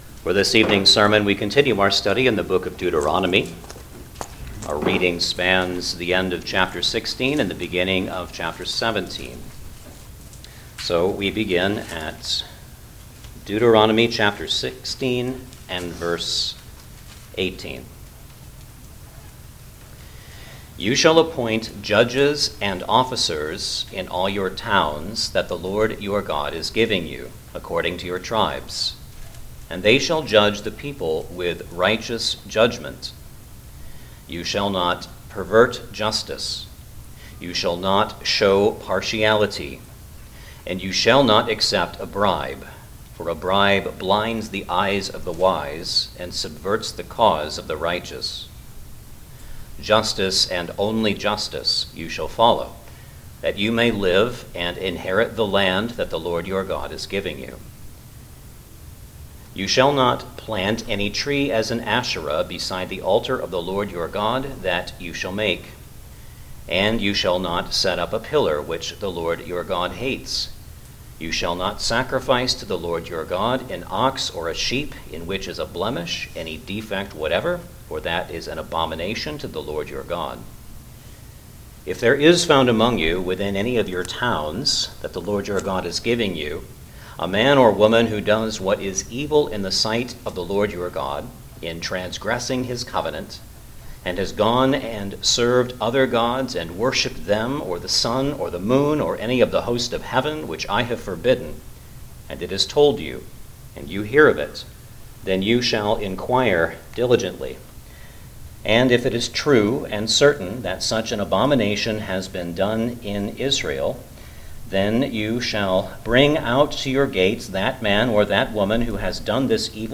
Deuteronomy Passage: Deuteronomy 16:18 – 17:13 Service Type: Sunday Evening Service Download the order of worship here .